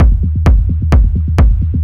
• heavy clicky techno kick loop.wav
heavy_clicky_techno_kick_loop_CQY.wav